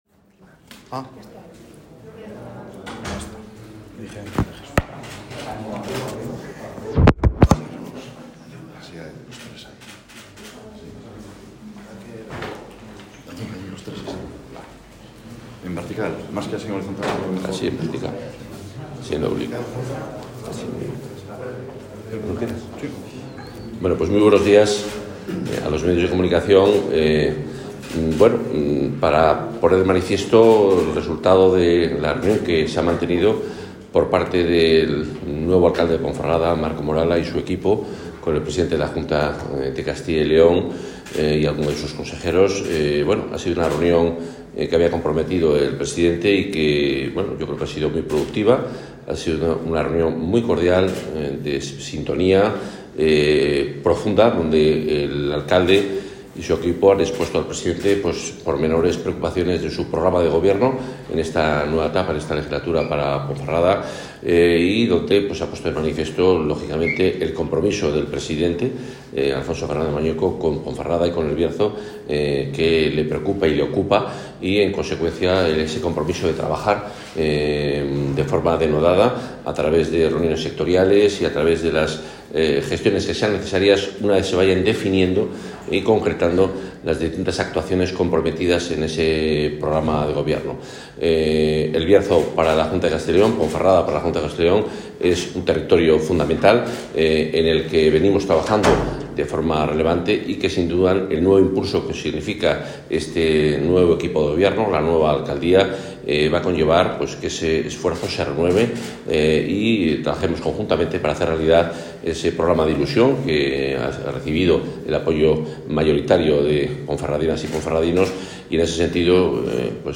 Al mismo, celebrado en Valladolid, han asistido la concejala Lidia Pilar Coca e Iván Alonso como miembros de la corporación local de Ponferrada recientemente constituida.
Audio delcaraciones: